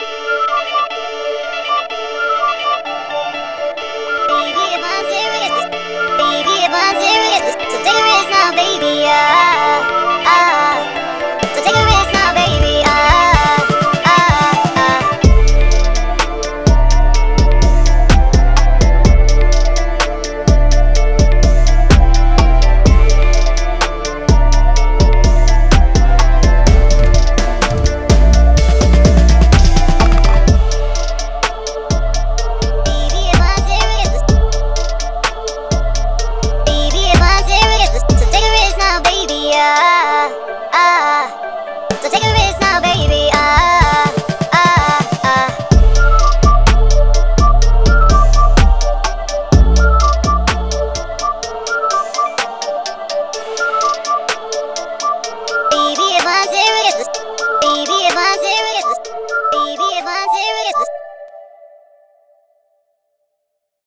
This week, the pack to work with was Igazi, a smooth afro-house/amapiano sample pack that had the Discord channel on fire right after its release.